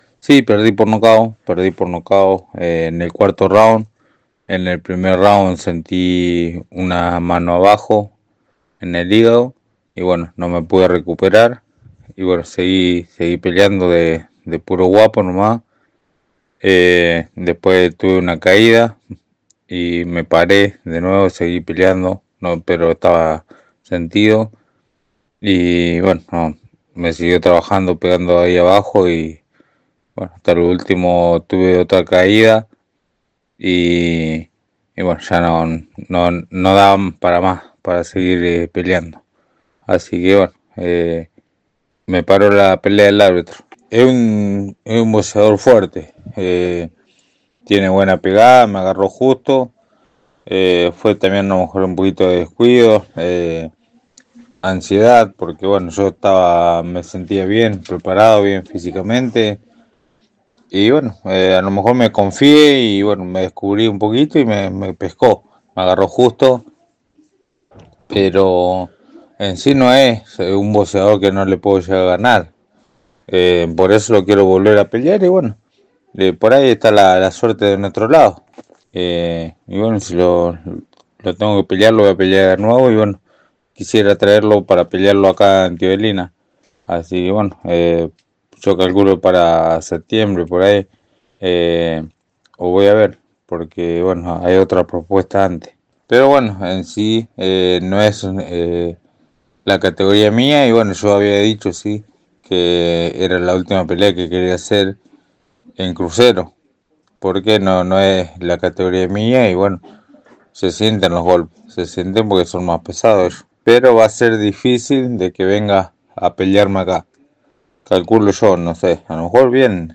Llegando a Teodelina entrevistamos a nuestro crédito local quien gentilmente explicó:-